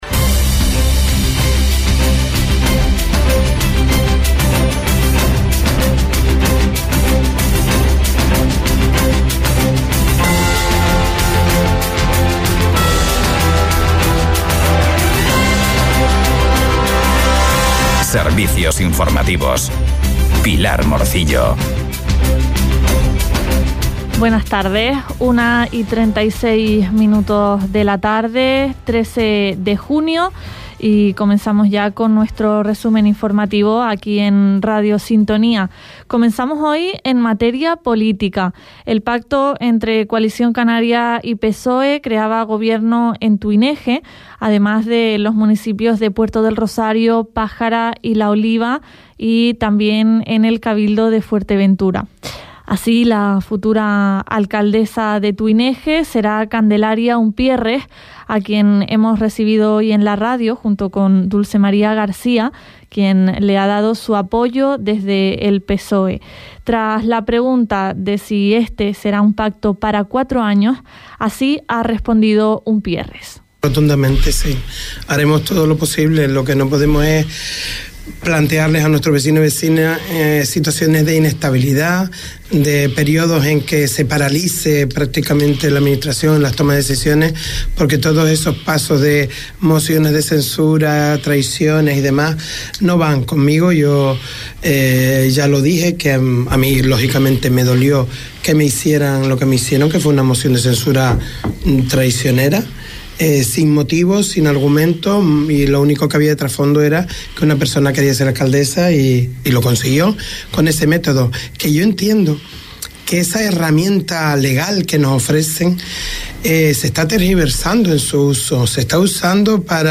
Informativos en Radio Sintonía - 13.06.23